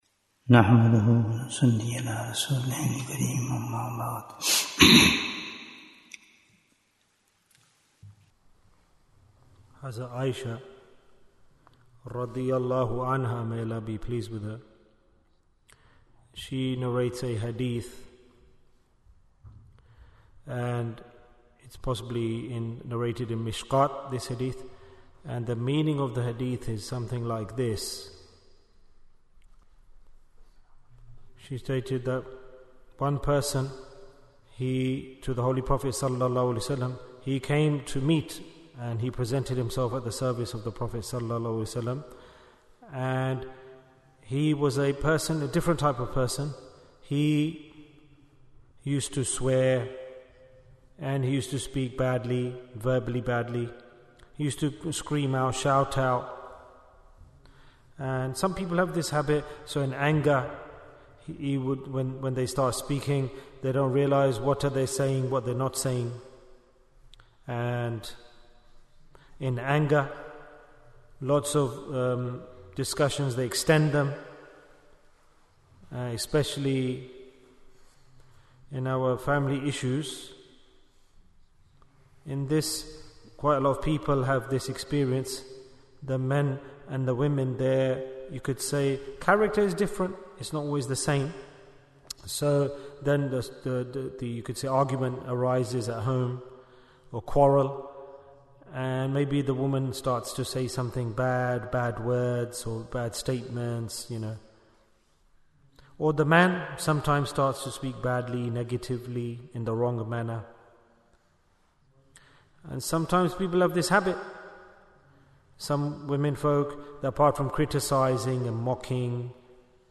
Bayan, 44 minutes 16th March, 2025 Click for Urdu Download Audio Comments Jewels of Ramadhan 2025 - Episode 19 - What is the Message of Ramadhan?